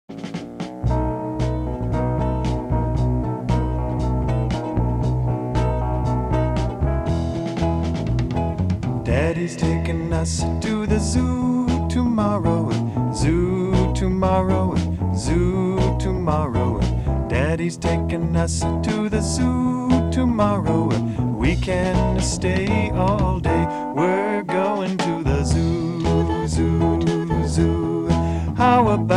Home > Folk Songs